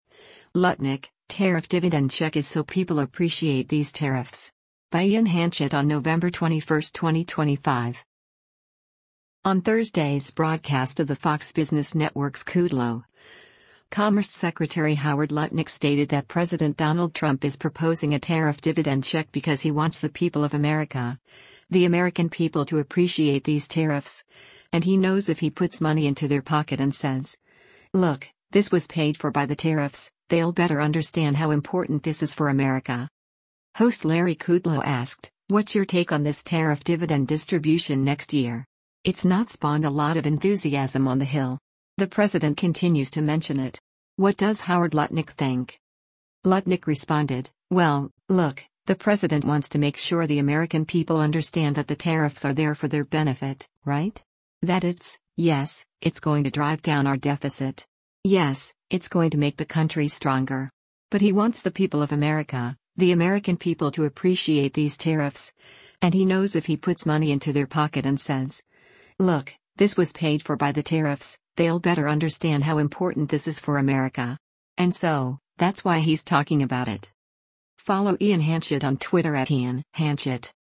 On Thursday’s broadcast of the Fox Business Network’s “Kudlow,” Commerce Secretary Howard Lutnick stated that President Donald Trump is proposing a tariff dividend check because “he wants the people of America, the American people to appreciate these tariffs, and he knows if he puts money into their pocket and says, look, this was paid for by the tariffs, they’ll better understand how important this is for America.”
Host Larry Kudlow asked, “What’s your take on this tariff dividend distribution next year?